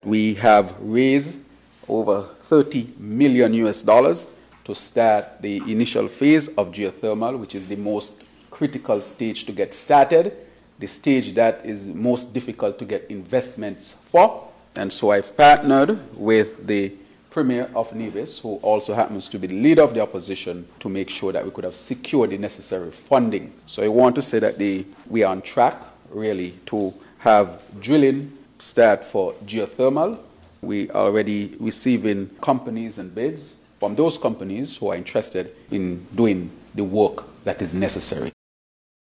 Prime Minister and Minister of Finance, the Hon. Dr. Terrance Drew, during a Sitting of the National Assembly on Thursday, updated the nation about the Geothermal project.
Prime Minister, Dr. Terrance Drew.